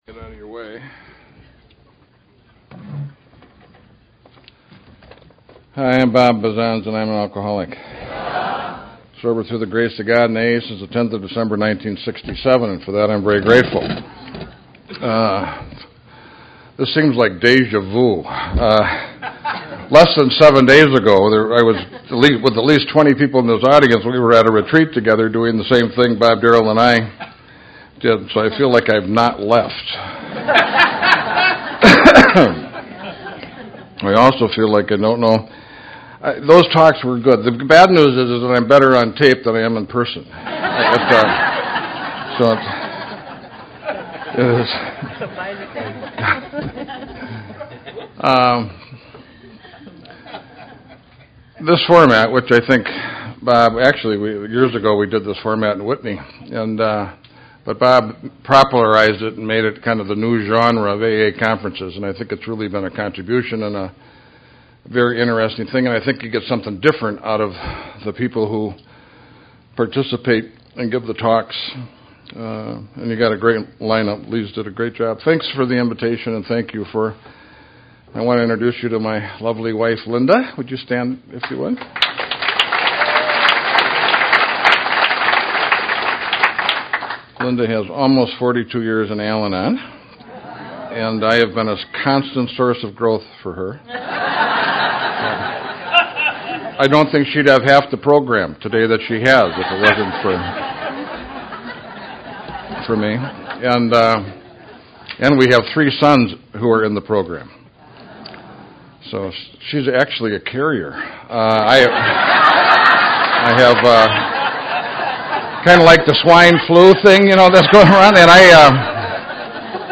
Steps 5-6-7, 19th. Spring Conference Cocoa Beach, FL. 2009